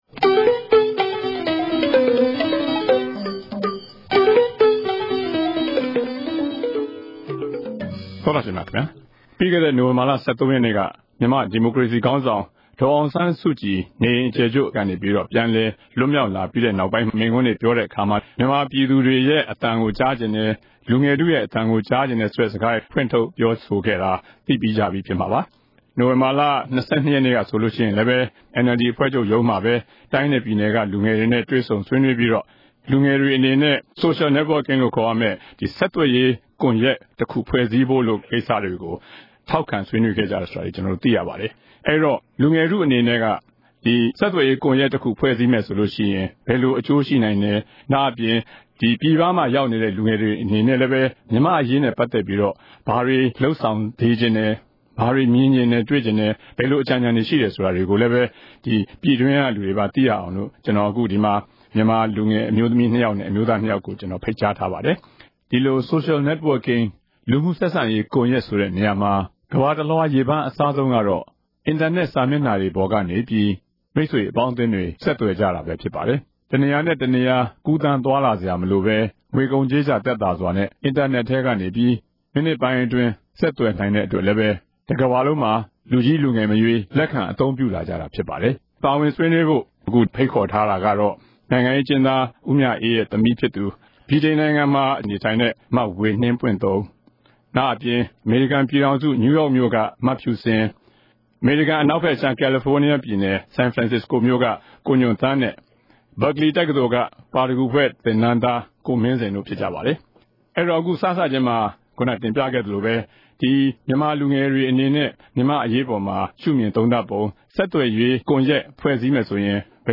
တနင်္ဂနွေစကားဝိုင်း။